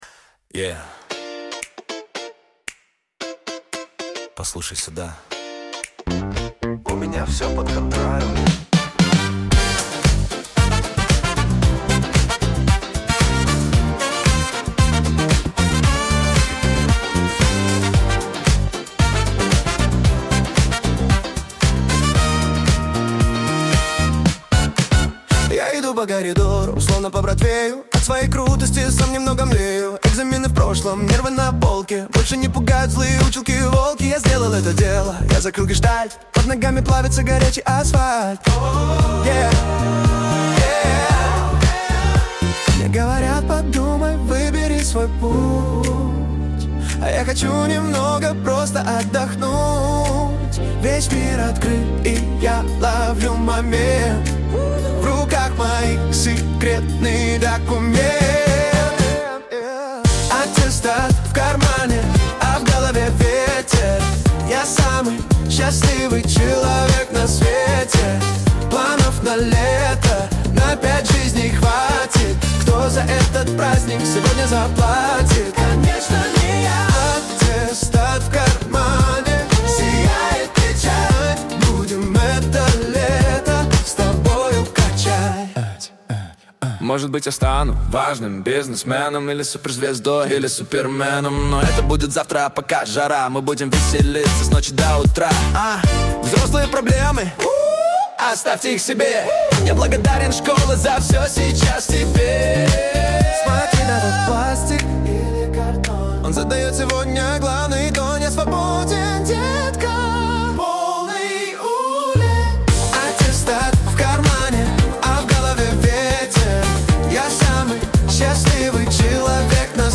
Здесь нет агрессии, только чистый стиль и уверенность.
Темп 118 BPM идеально подходит для дефиле.
Солнечный фанк (118 BPM).